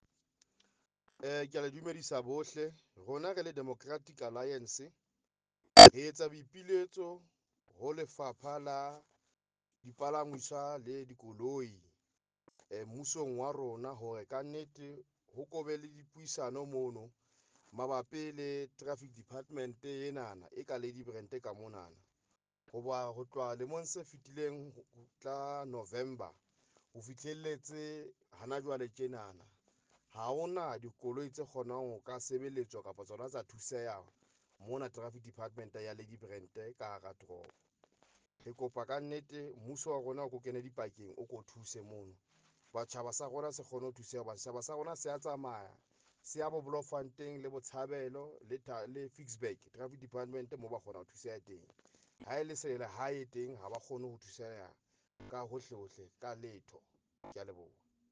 Afrikaans soundbites by Cllr Nicky van Wyk.